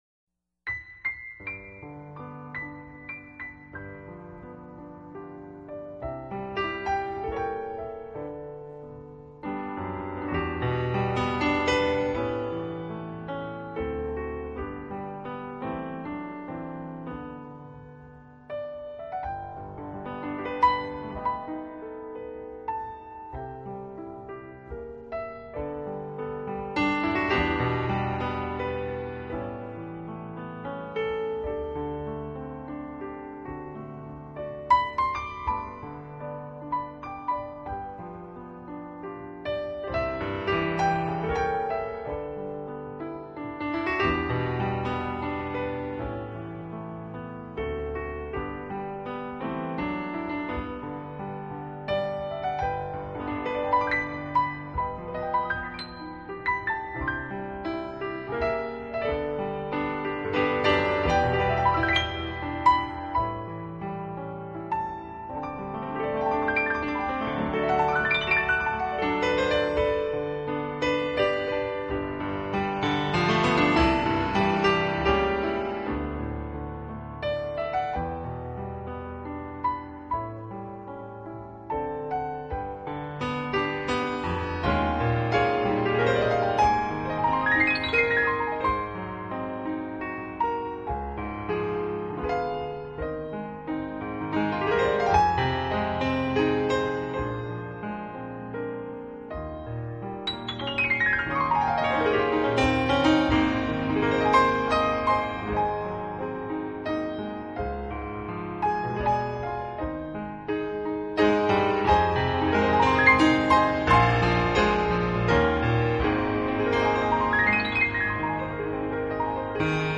音乐类型：钢琴